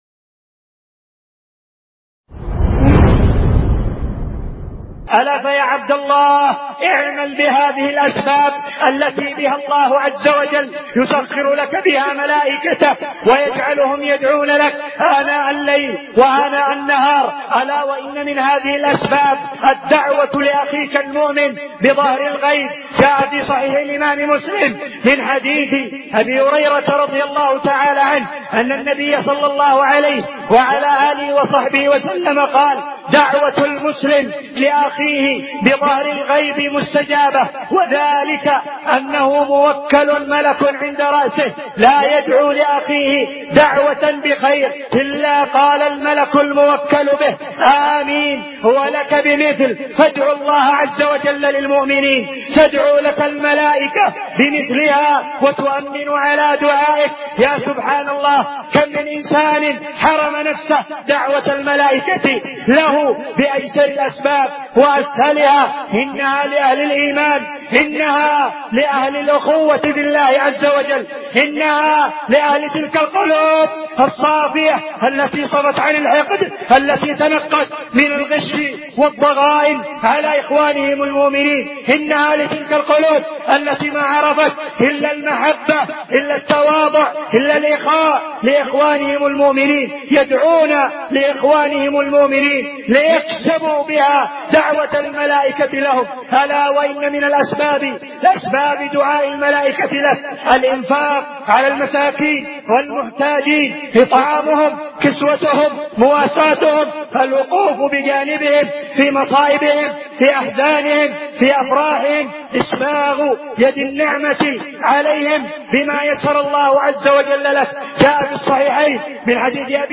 من خطبة
ألقيت بمسجد الرحمن مدينة الجراحي الحديدة اليمن